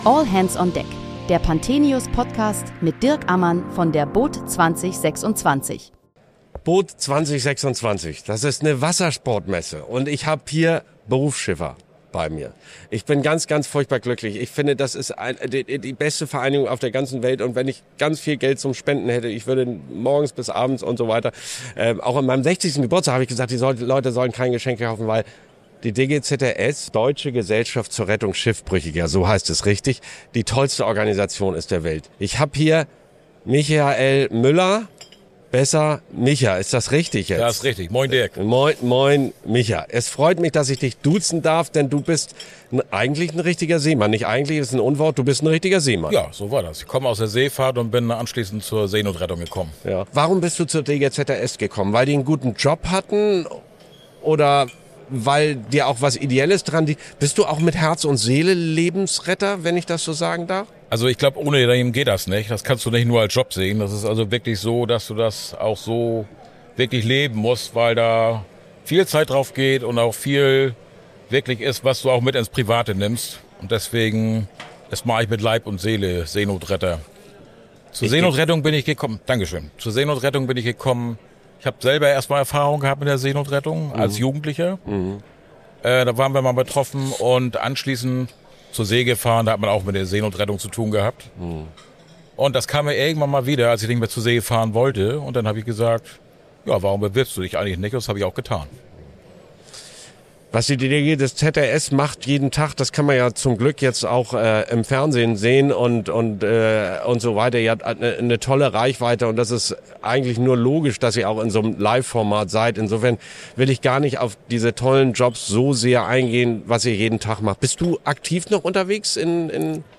Während der boot Düsseldorf 2026 sprachen wir täglich mit Gästen aus der Branche über aktuelle und kontroverse Themen rund um den Wassersport.